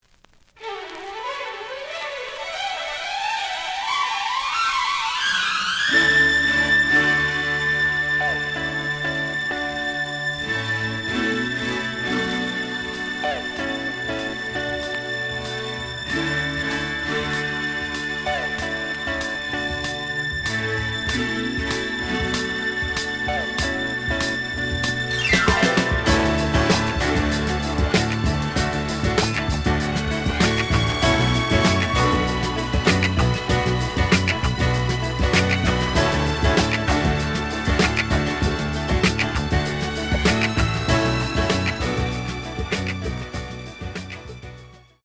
試聴は実際のレコードから録音しています。
●Format: 7 inch
●Genre: Funk, 70's Funk